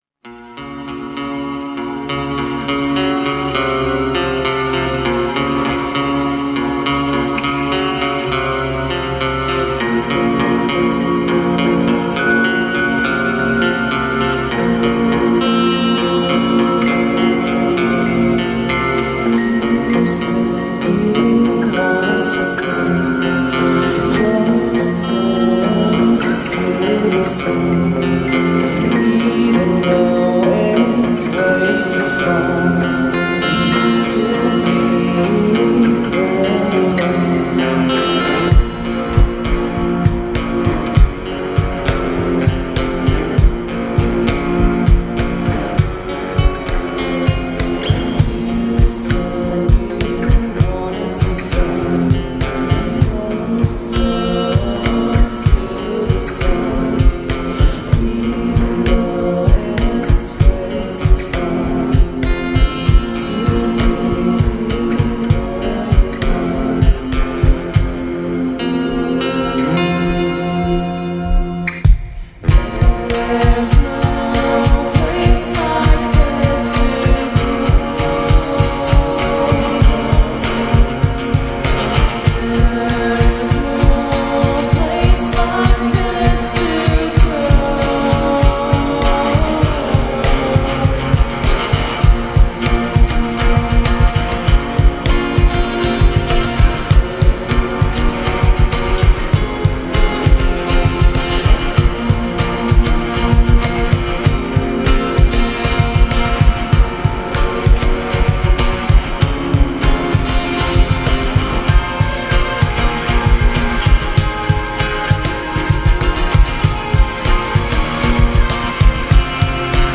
in (acoustic) session – Zagreb Croatia
post-rock
one that also took in shoegaze and noise rock.